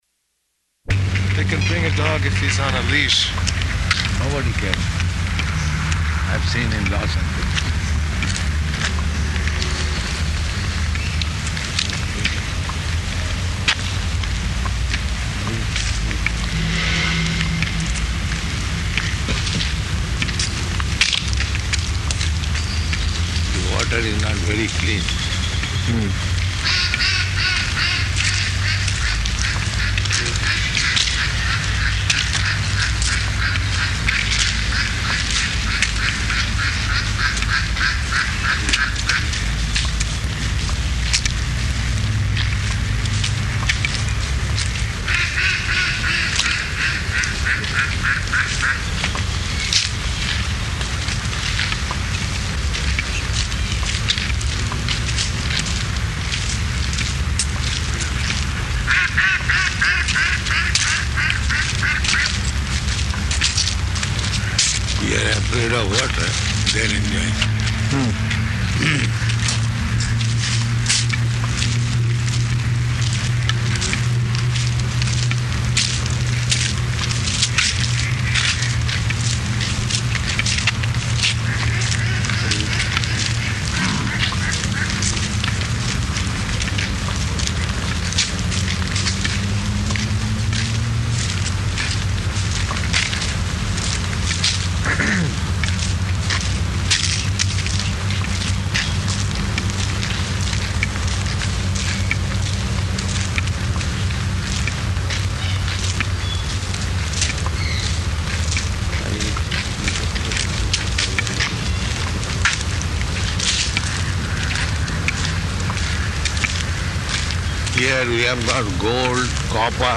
-- Type: Walk Dated: May 7th 1975 Location: Perth Audio file